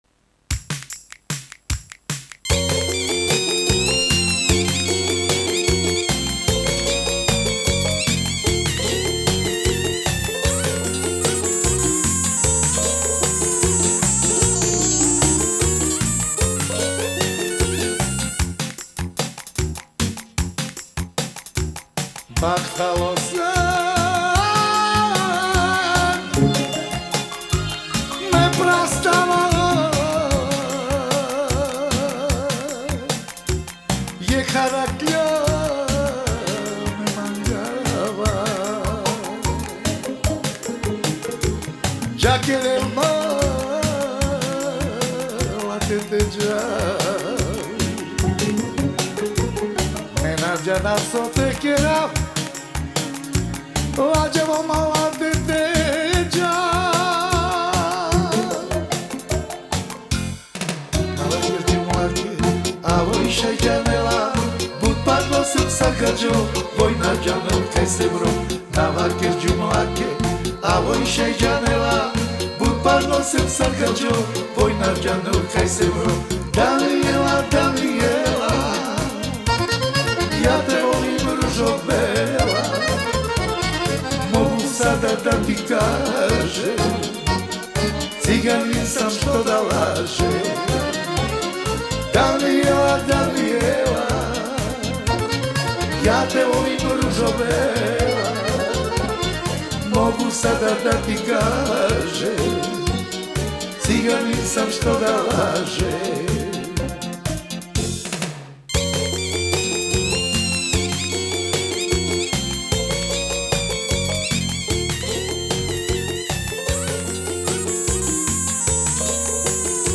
Genre: Gypsy Jazz, World, Ethnic, Folk Balkan